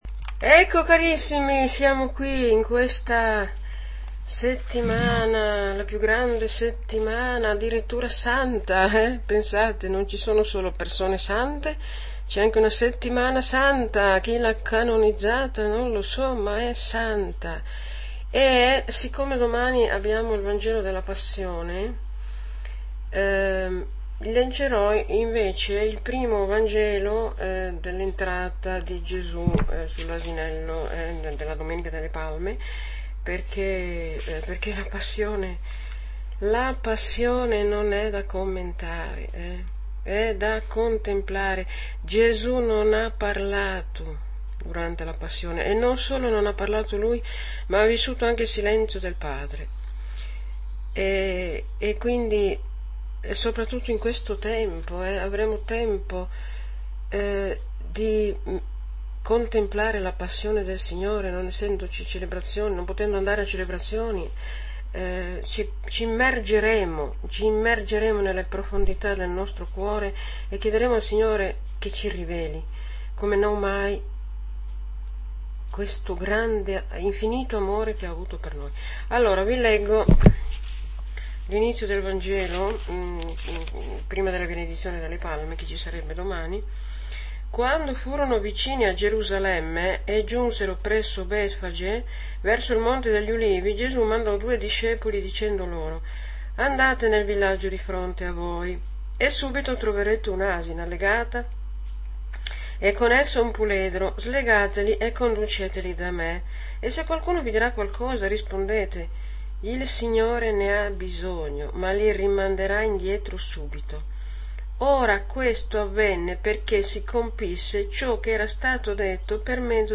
AUDIO Audio commento alla liturgia - Mt 26,14-27,66